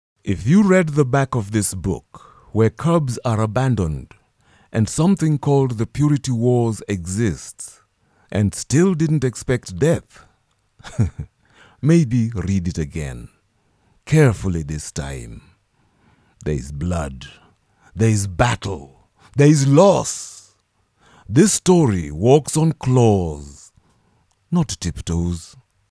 Voice demo 2
I do voice-overs